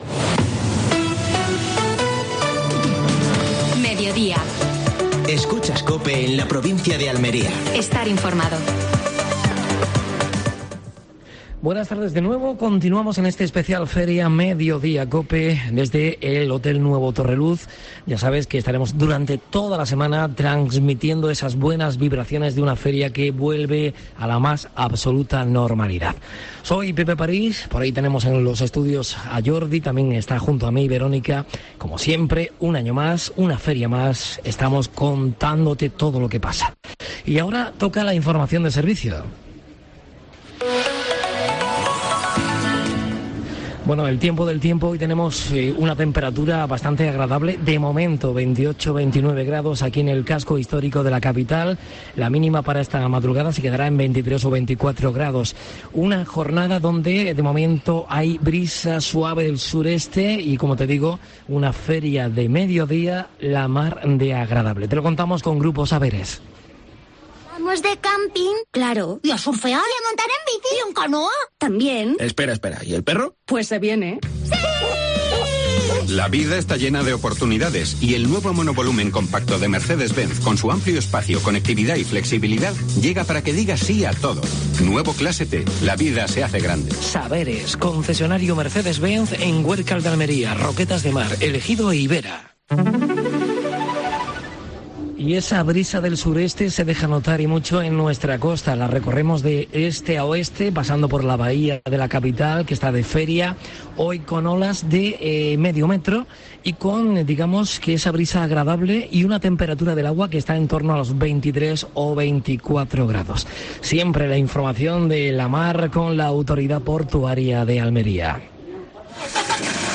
AUDIO: Última hora en Almería, desde el Hotel Nuevo Torreluz.